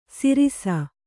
♪ sirisa